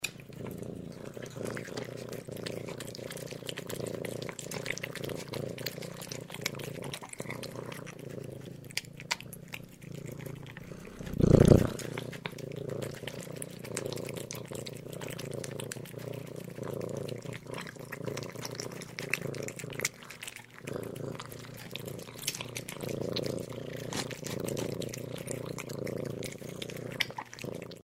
Котята пьют молоко, а кошка мурлычет